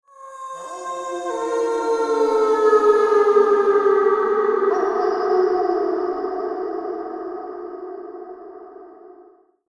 دانلود آهنگ گرگ ها در جنگل ترسناک از افکت صوتی طبیعت و محیط
جلوه های صوتی
دانلود صدای گرگ ها در جنگل ترسناک از ساعد نیوز با لینک مستقیم و کیفیت بالا